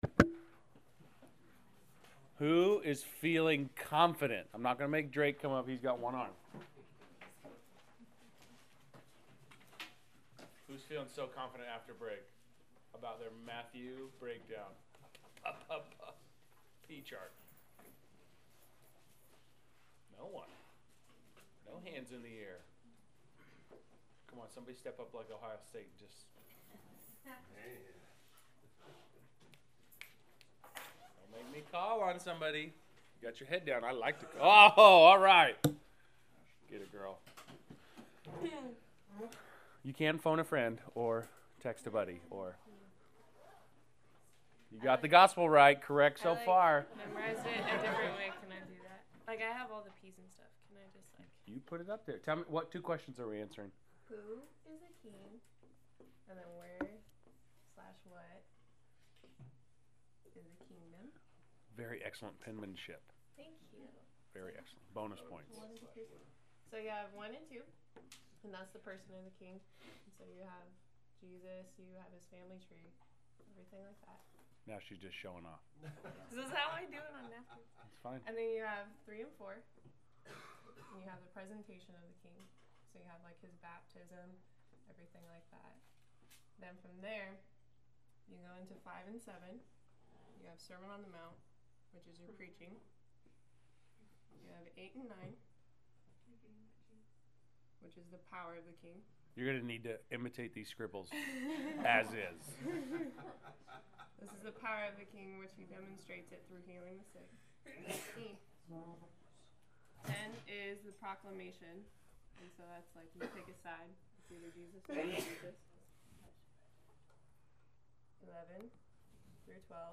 Class Session Audio January 14